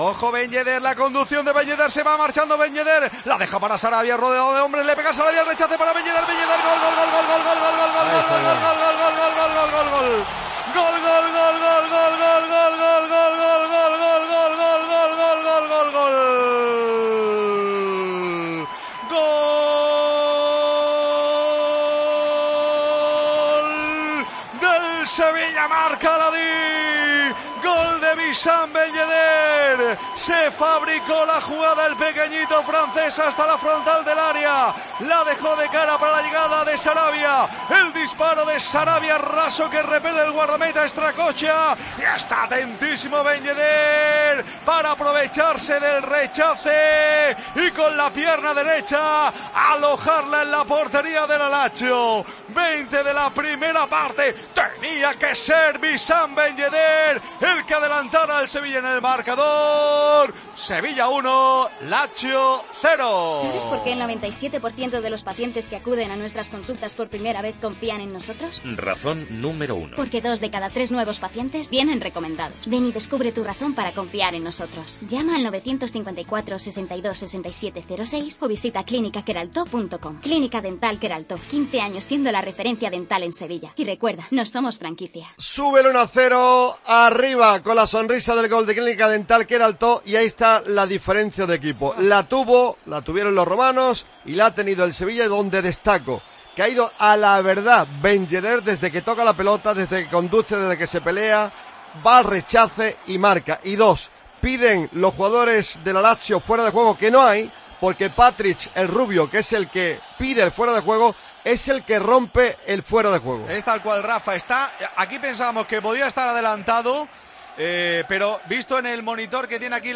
Así sonó el triunfo del Sevilla ante la Lazio en Cope Más Sevilla